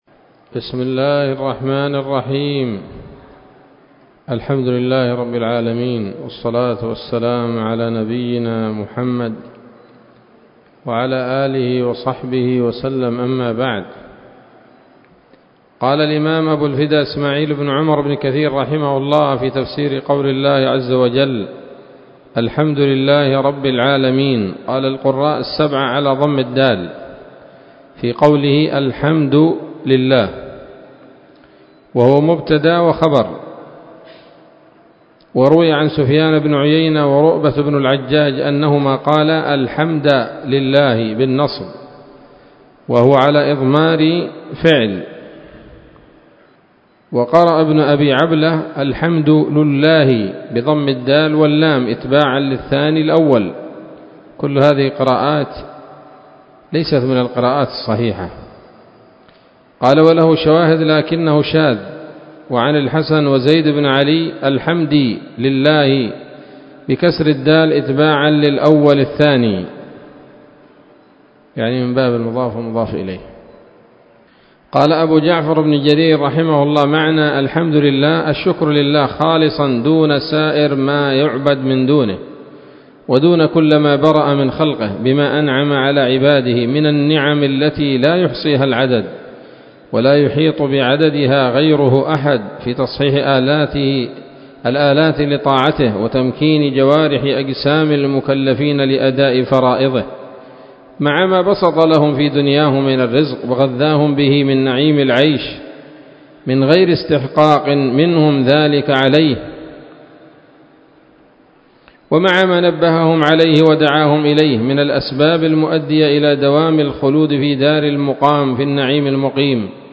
الدرس الرابع عشر من سورة الفاتحة من تفسير ابن كثير رحمه الله تعالى